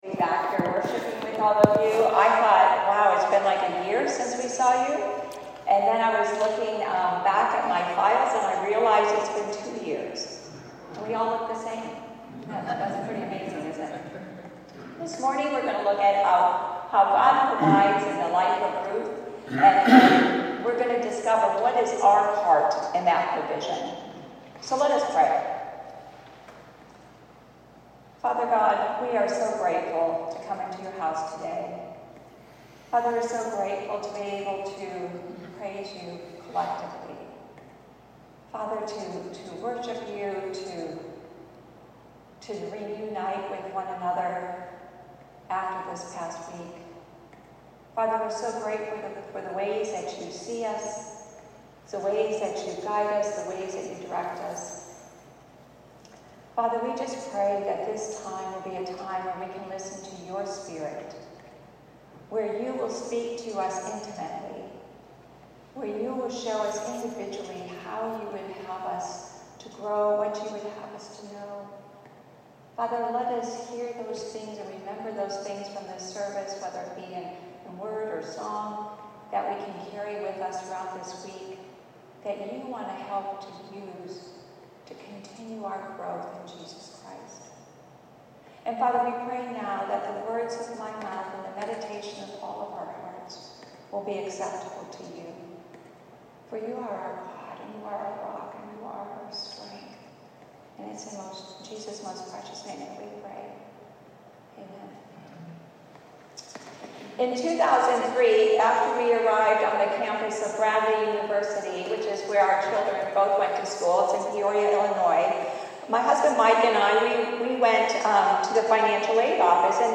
Sermon-28-Sep-25.mp3